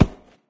grass2.ogg